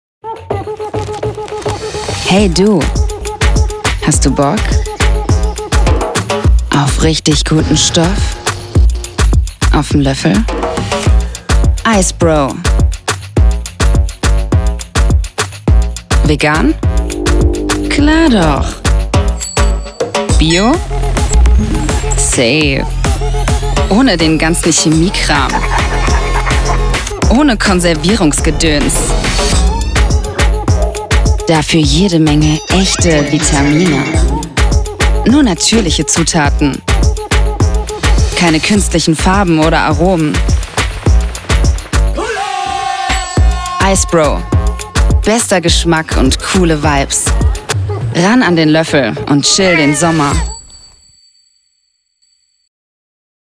sehr variabel
Mittel minus (25-45)
Commercial (Werbung)